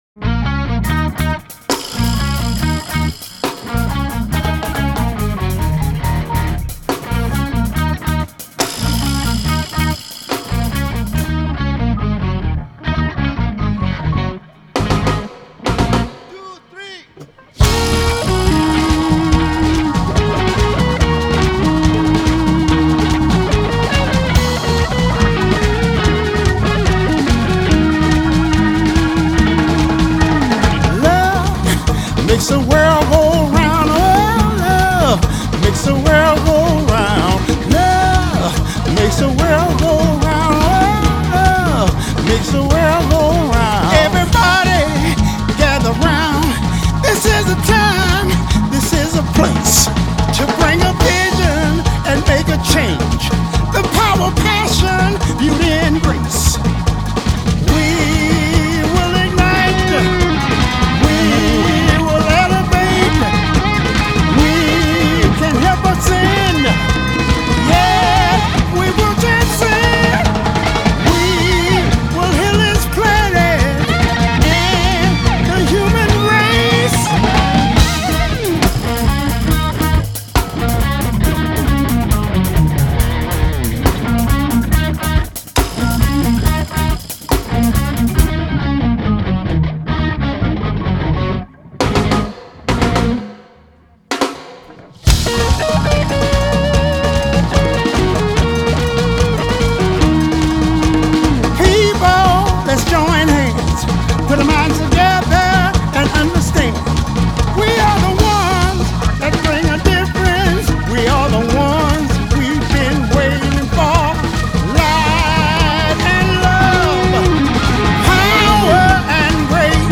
Genre: Classic Rock, Blues Rock